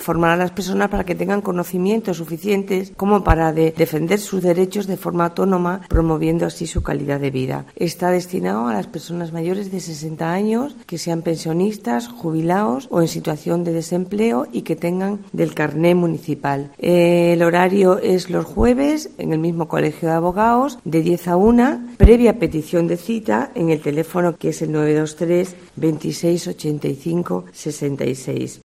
La concejala Isabel Macías explica las condiciones del servicio